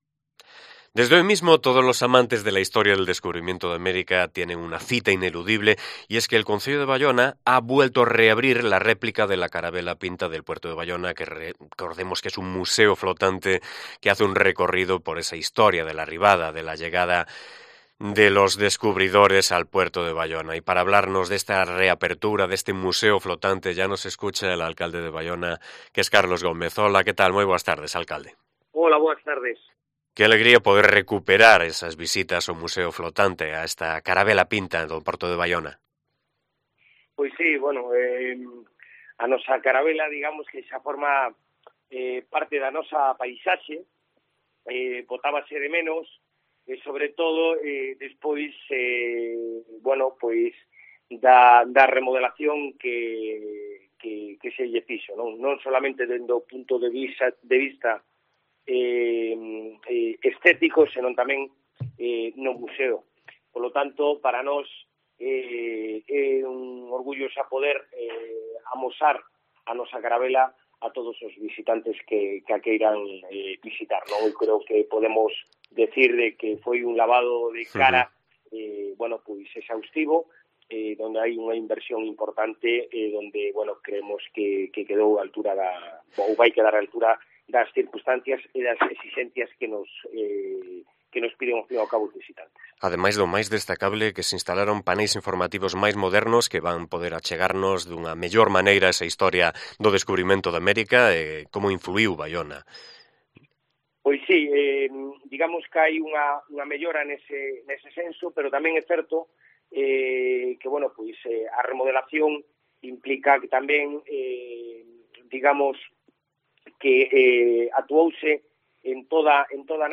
Carlos Gómez, alcalde de Baiona, nos habla sobre la reapertura del museo flotante de la Carabela Pinta